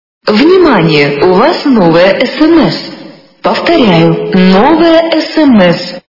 » Звуки » для SMS » Звук для СМС - Внимание, у Вас новое СМС
При прослушивании Звук для СМС - Внимание, у Вас новое СМС качество понижено и присутствуют гудки.